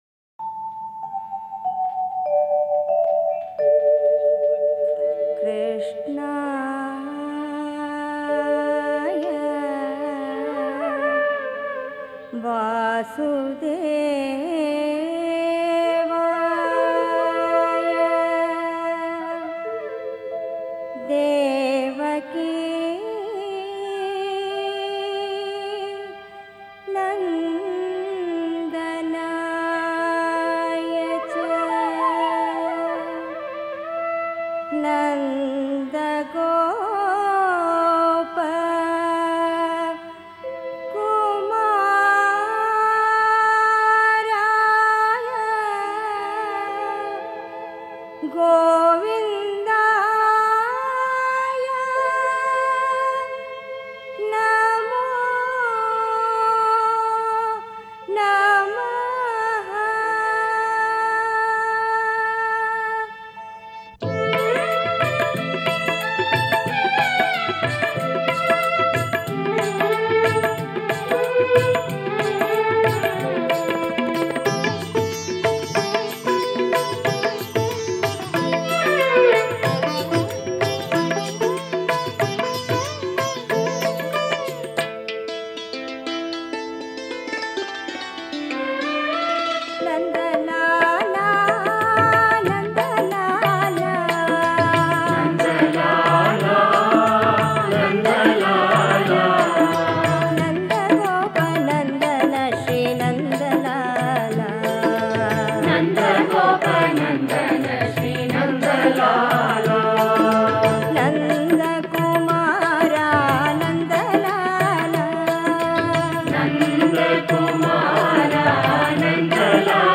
Home | Krishna Bhajans | 59 NANDALALA NANDALA